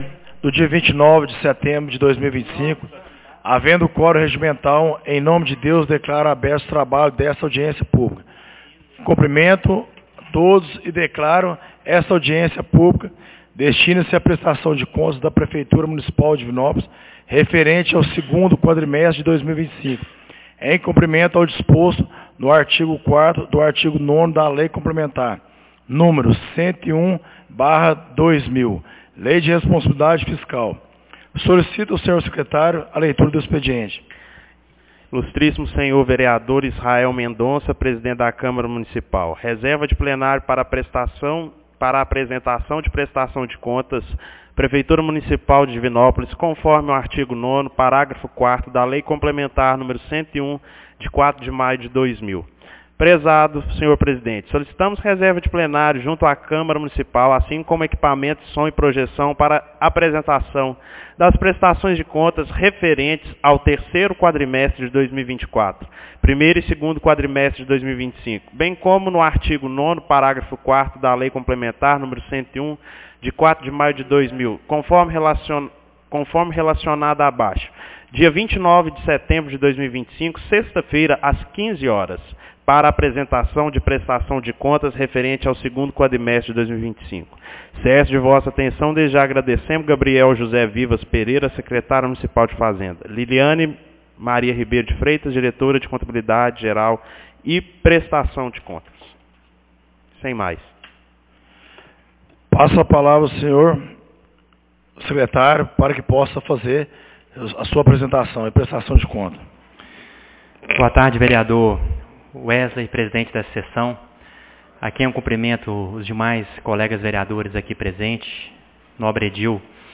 Audiencia Publica Prestação de Contas do 2ª Quadrimestre de 2025 29 de setembro de 2025